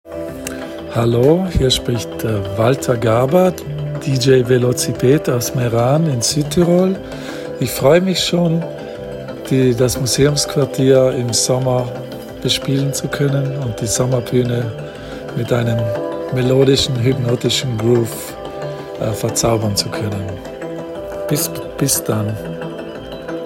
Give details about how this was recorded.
Live DJ-Set at MQ Summer Stage